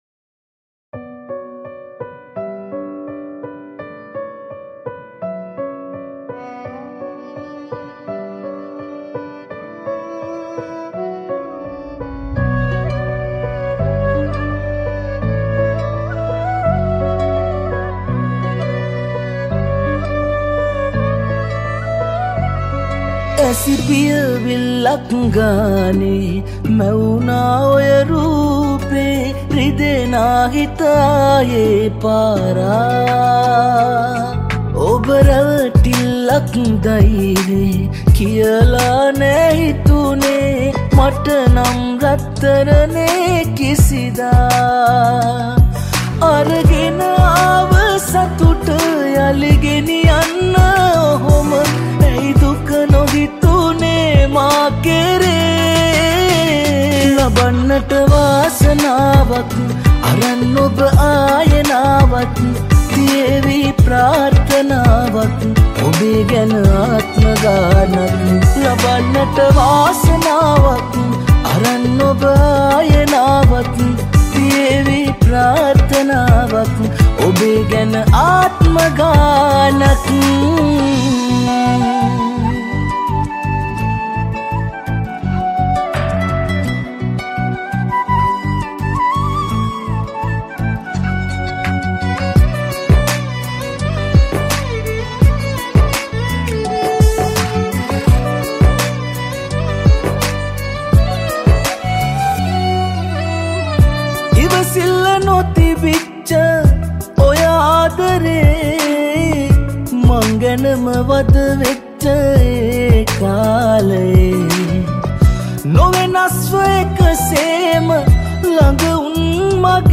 High quality Sri Lankan remix MP3 (4).